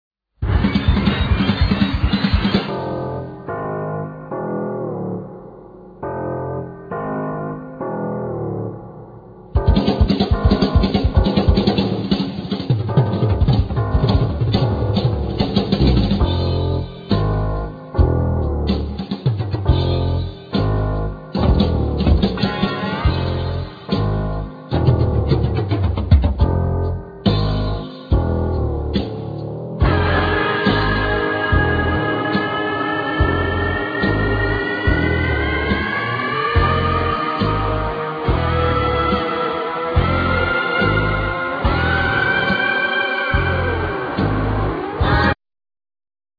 Keyboards
Guitar,Flute
Vocal,Keyboards
Vocal,Guitar
Bass guitar
Drums